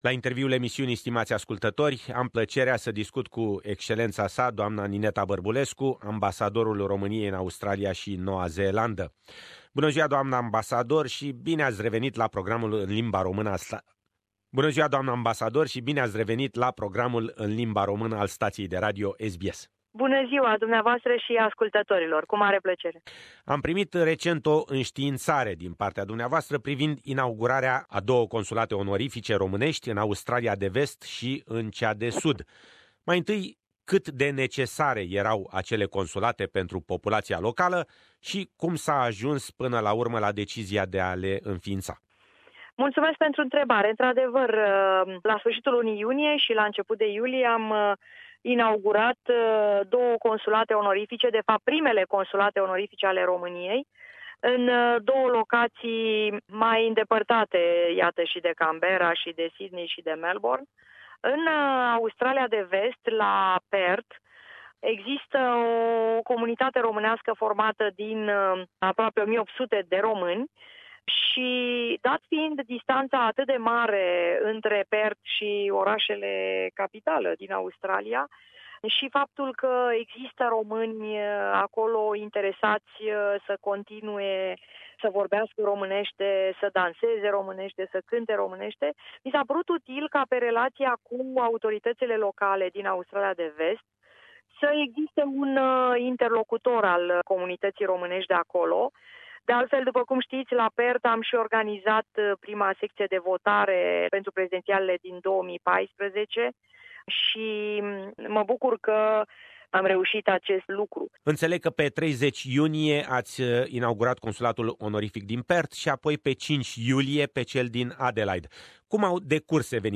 New Romanian Honorary Consulates in Perth and Adelaide - Interview with Romanian Ambassador in Australia and New Zealand, Nineta Barbulescu